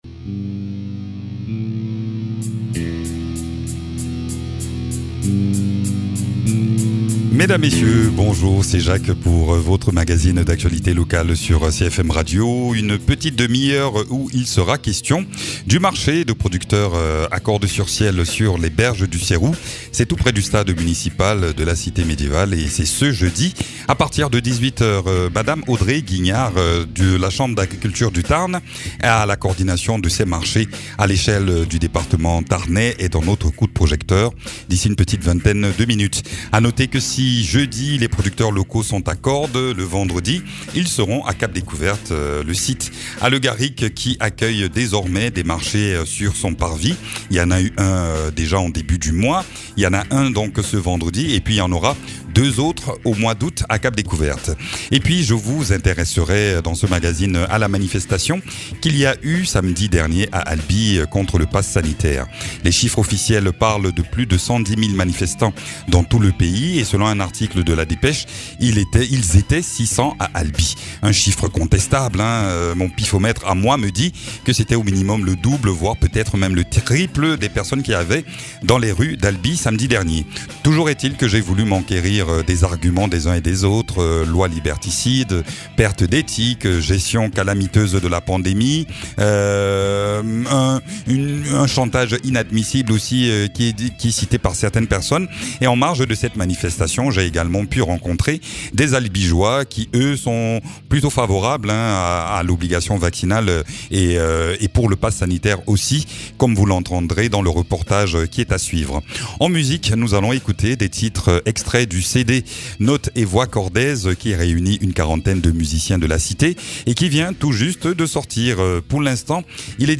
Des milliers de manifestants dans les rues d’Albi samedi dernier pour demander la suppression de l’obligation de pass sanitaire. Dans ce reportage, nous entendons leurs arguments et les raisons de leurs mécontentements.